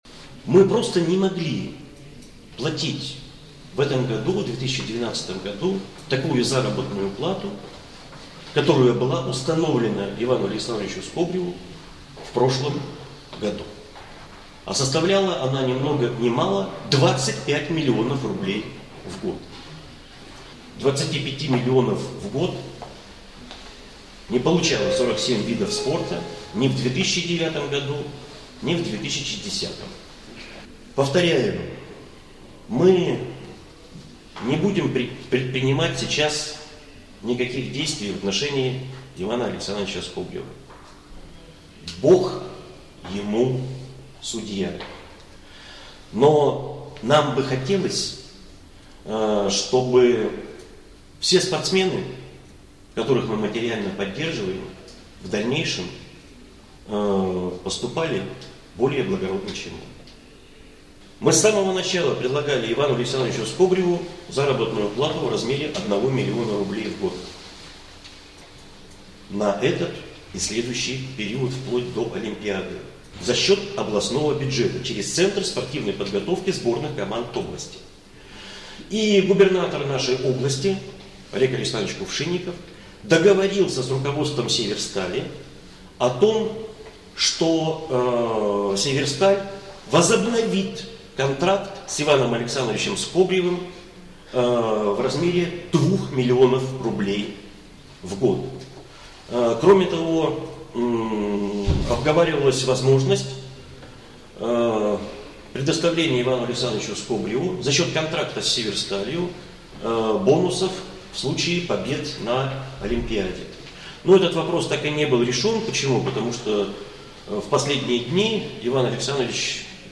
Такое заявление на встрече с журналистами сделал председатель областного Комитета по физической культуре, спорту и молодежной политике Олег Димони, сообщает ИА "СеверИнформ - Новости Вологодской области".